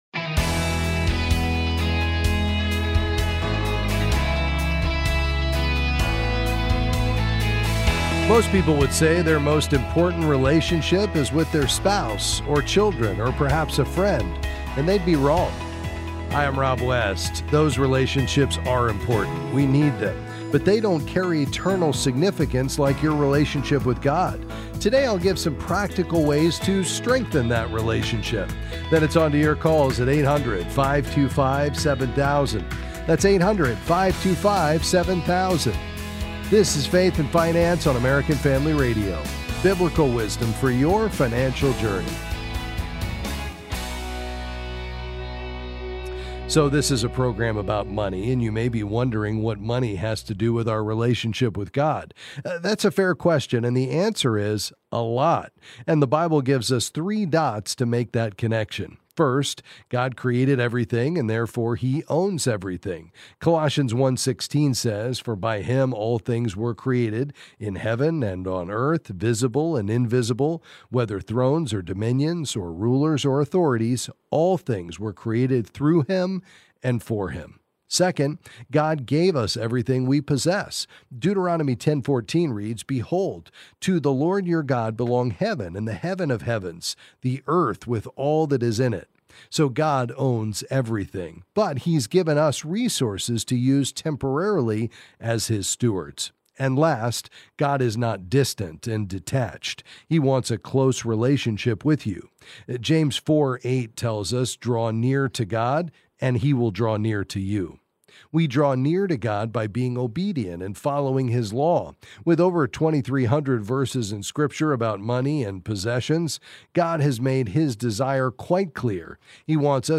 Then he’ll answer your calls about various financial topics.